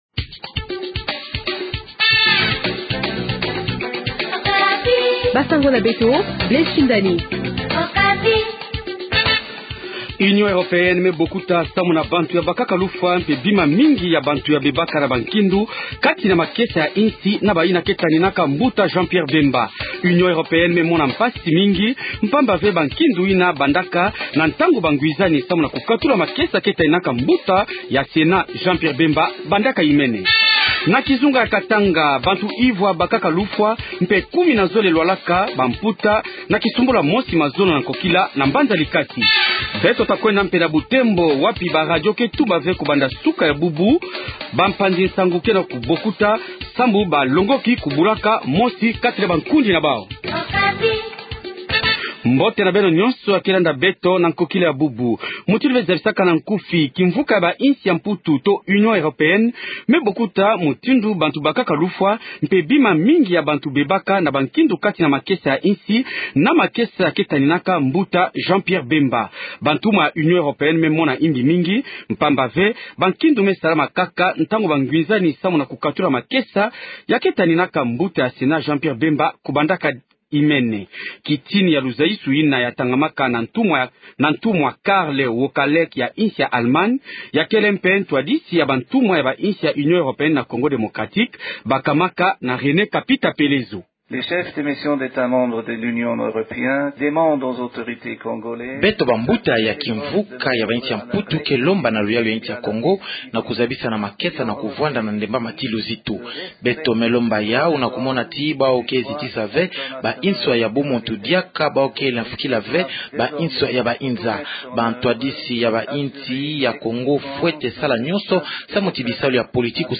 Journal Kikongo Soir
Extrait de cette déclaration lue par l’ambassadeur Karl Wokalek de l’Allemagne, , pays qui assume actuellement la présidence de l’Union Européenne.